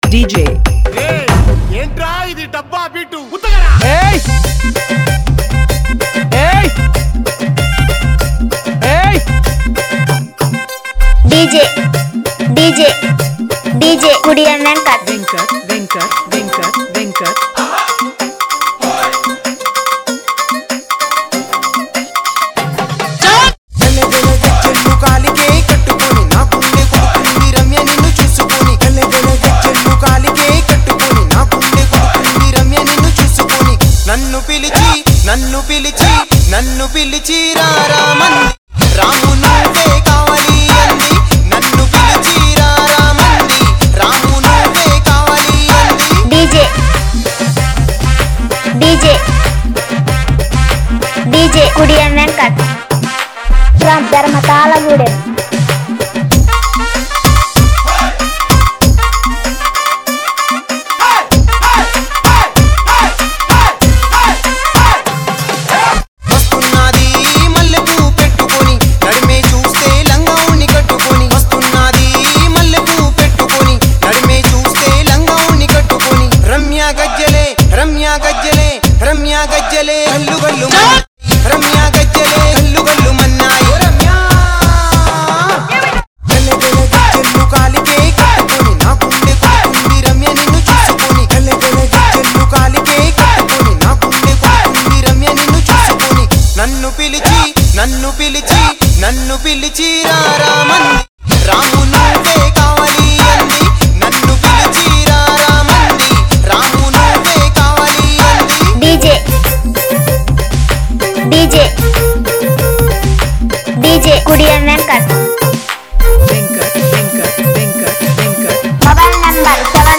Genre DJ , Folk Songs